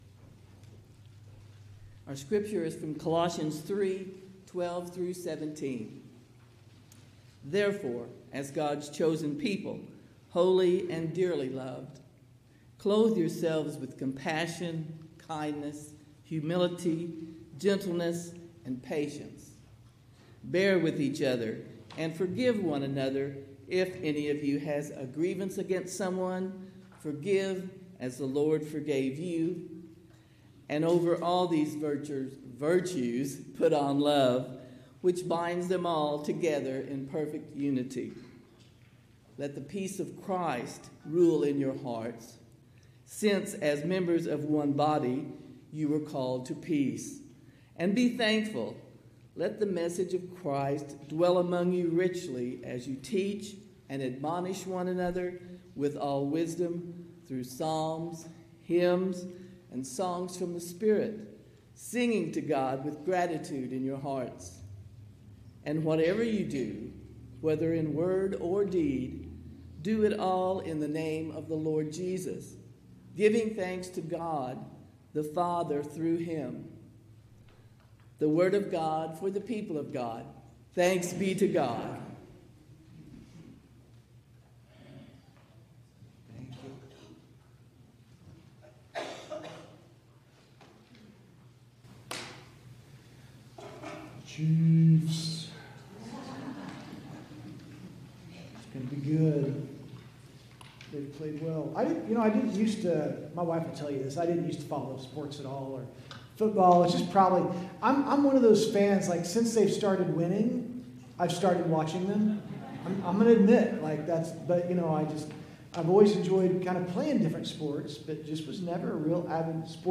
Sermons | First United Methodist Church
*Due to a technical issue, there is only recorded audio for the first 20 minutes of Worship Service; video kicks in at around the 20 minute mark.*